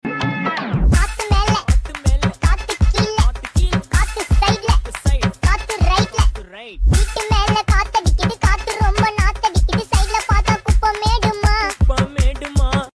Gy6 150cc racing 4 stroke sound effects free download
Gy6 150cc racing 4 stroke motorcycle & scooter